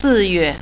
sì yùe